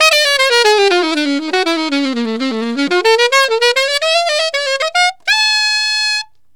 Alto One Shot in Ab 01.wav